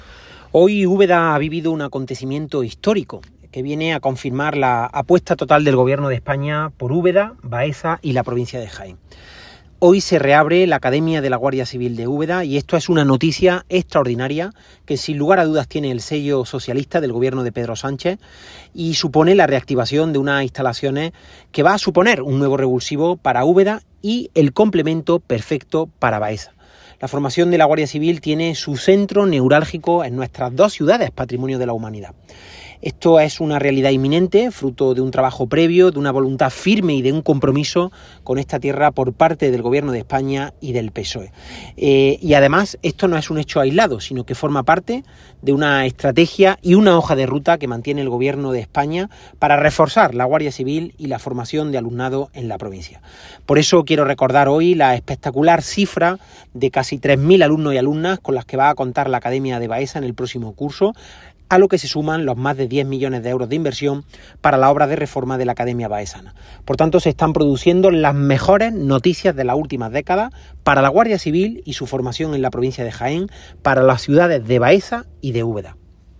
Cortes de sonido # Juan Latorre